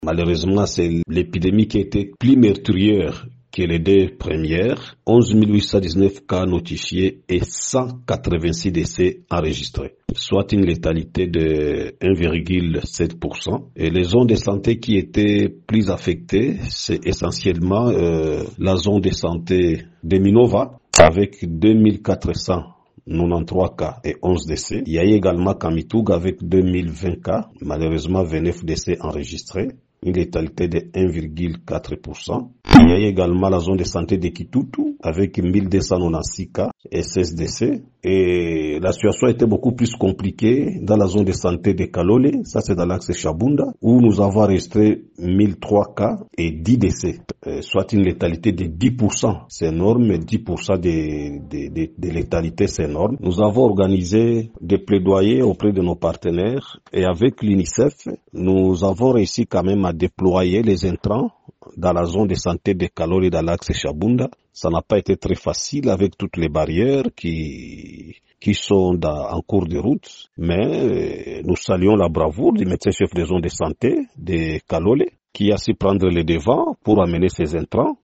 dans un entretien avec Radio Maendeleo